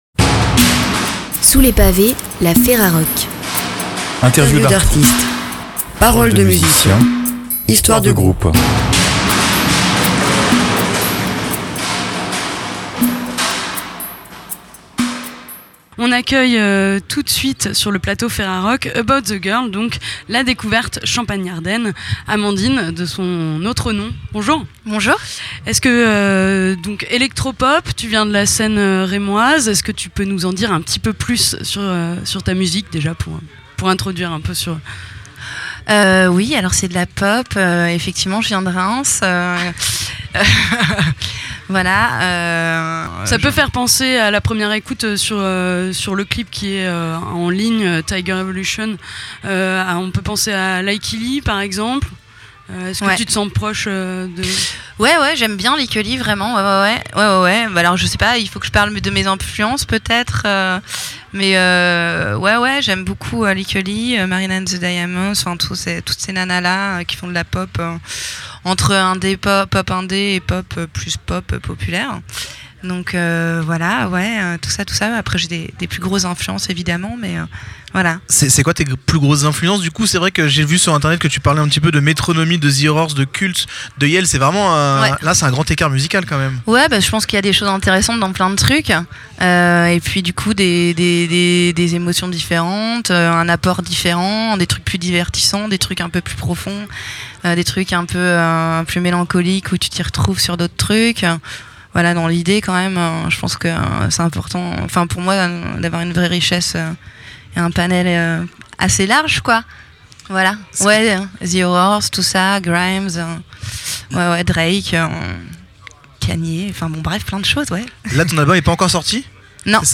Vous êtes l'artiste ou le groupe interviewé et vous souhaitez le retrait de cet interview ? Cliquez ici.